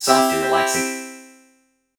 Music 171 assignment 7 (due Mar. 8): making a chord with comb filters
Assignment 7 is to use three recirculating comb filters to impose pitches on a vocal sample of the words "soft and relaxing" spoken by some bygone radio announcer.
Then figure out how many milliseconds are in the periods of the MIDI notes 60, 64, and 67 (the pitches of a simple C major chord, called a triad).
The comb filters should have recirculation gains close to, but not equal to, 1 to get them to ring at the three pitches.